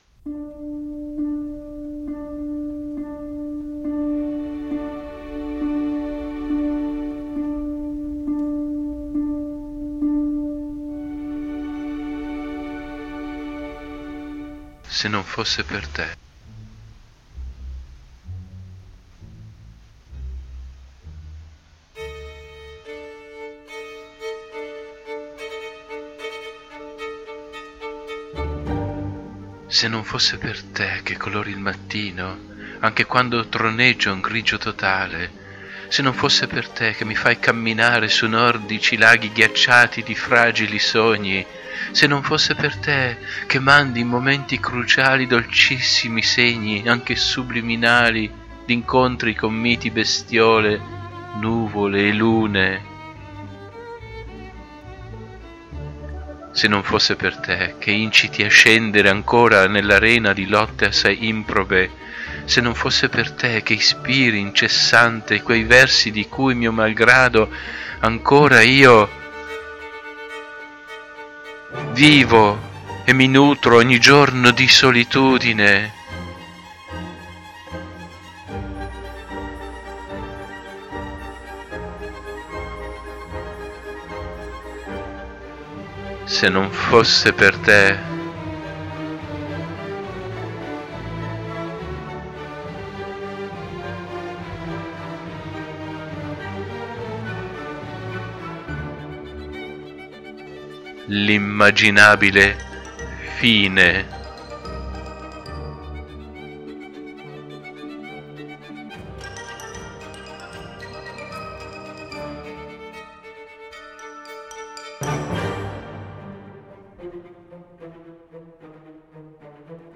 It is in the key of G minor.